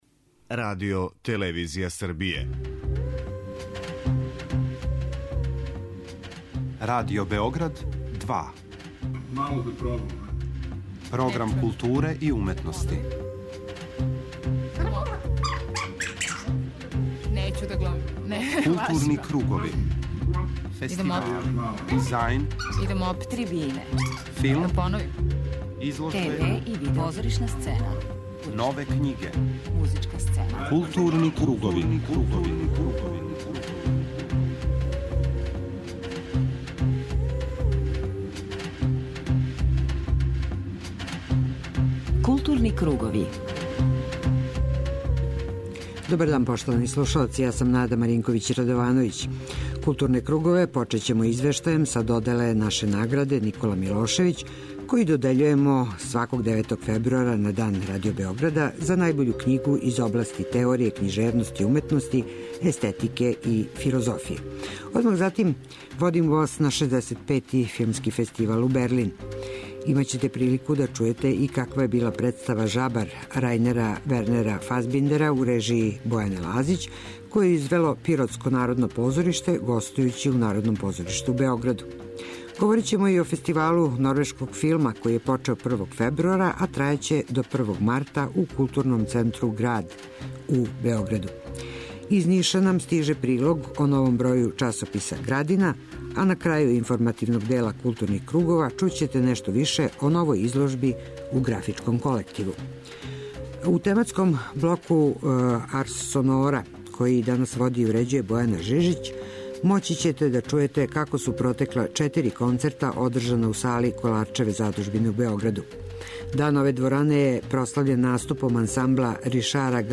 У блоку Арс сонора моћи ћете да чујете како су протекла четири концерта одржана у сали Коларчеве задужбине у Београду.
У оквиру Коларчевог подијума камерне одржан је концерт Quatro per due , на коме су се публици представили један клавирски дуо и један дуо удараљки.